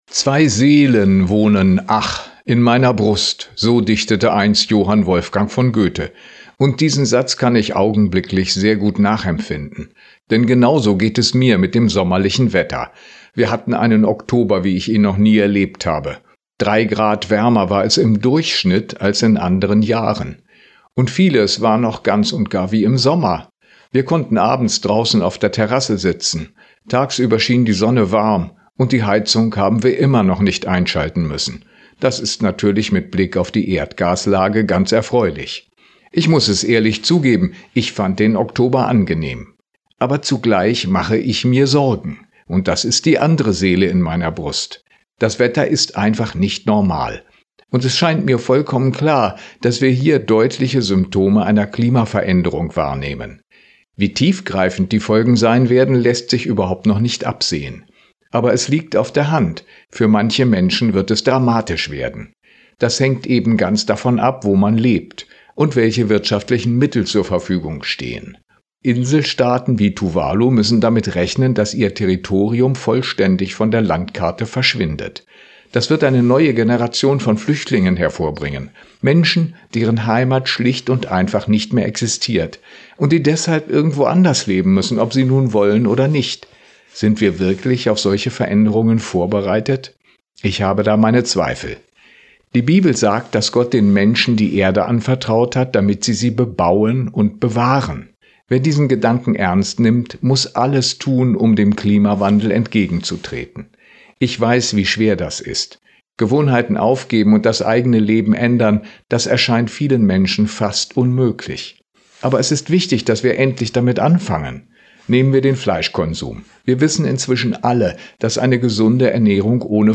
Radioandacht vom 2. November